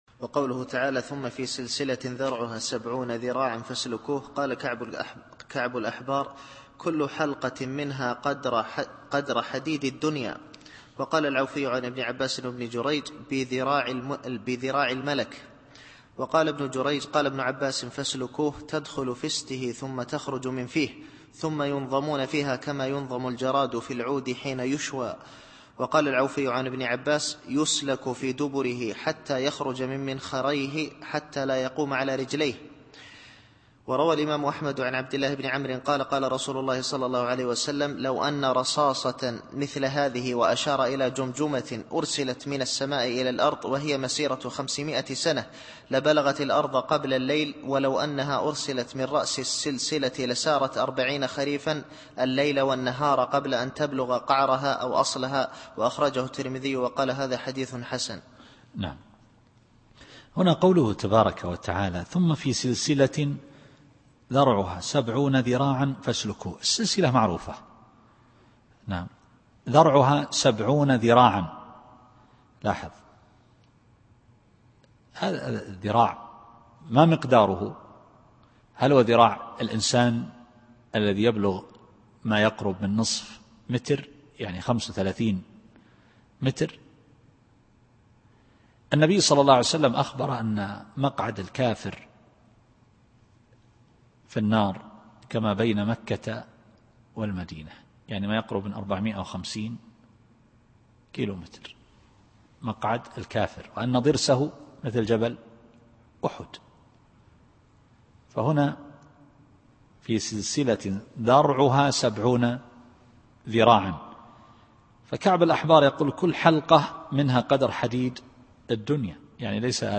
التفسير الصوتي [الحاقة / 32]